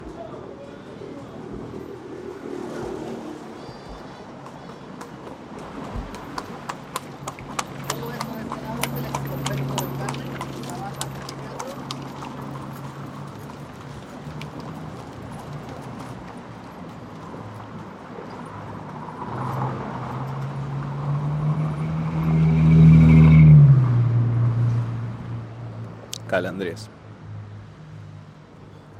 Tag: 墨西哥 calandras 城市 城镇